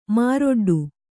♪ māroḍḍu